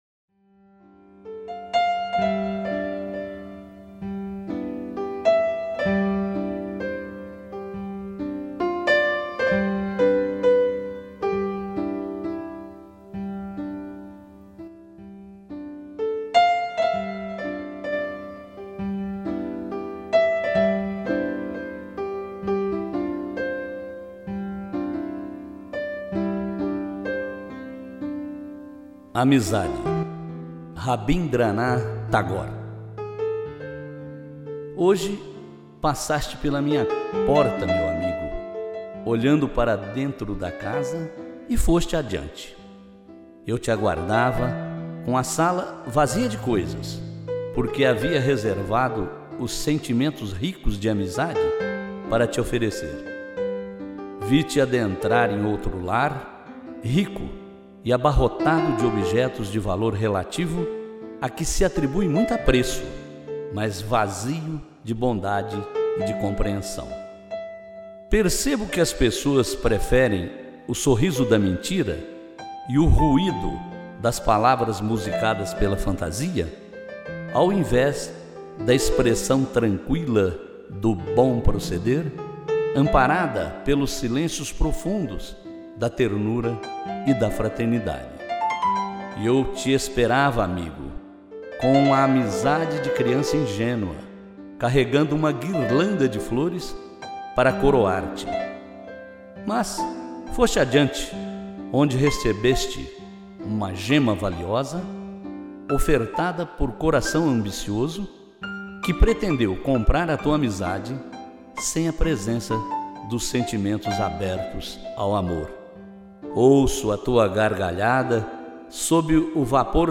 declamação